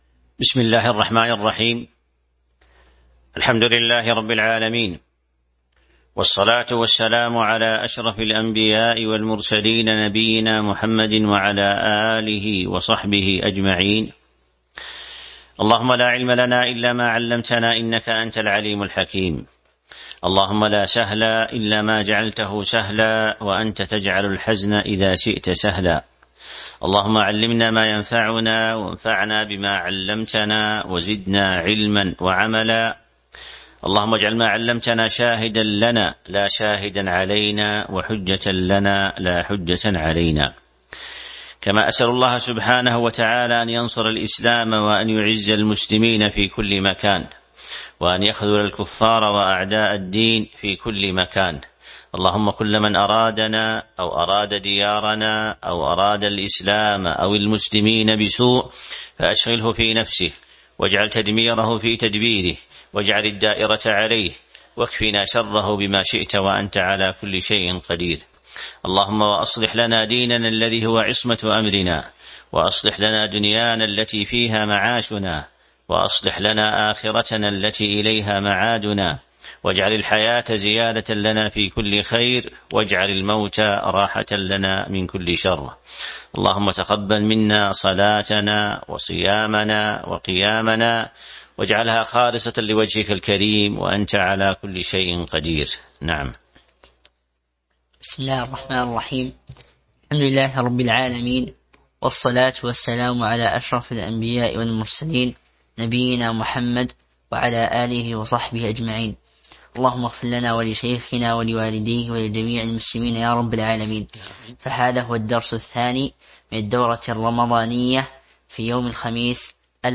تاريخ النشر ١٠ رمضان ١٤٤٢ المكان: المسجد النبوي الشيخ